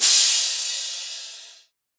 Crashes & Cymbals
Crash - Southside.wav